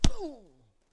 描述：在演播室录制了两个带有反应声音的拳头
标签： 扑灭 攻击 冲床 冲床 吸盘 命中
声道立体声